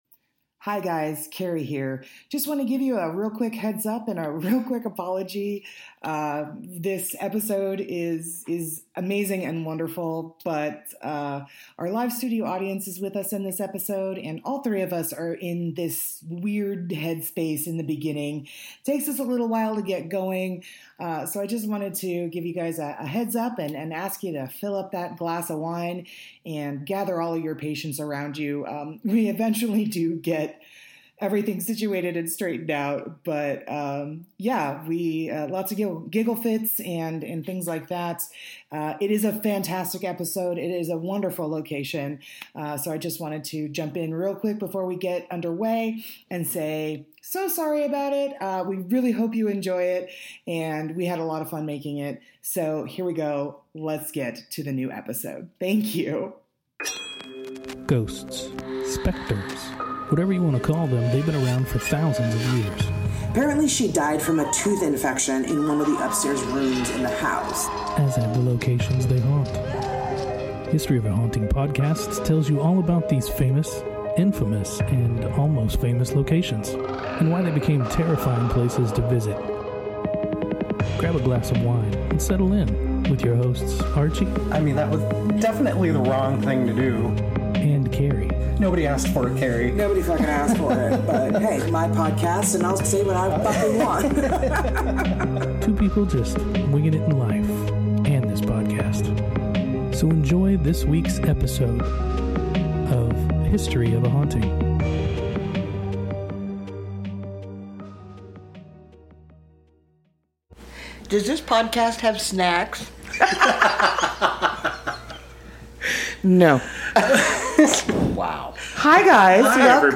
Once we get through the laugh fest, we tell you all about this amazing old hospital, turned hotel in the historic Arizona mining town of Jerome, AZ! It's got it all, grisly deaths, creepy ghosts, and phantom phone calls.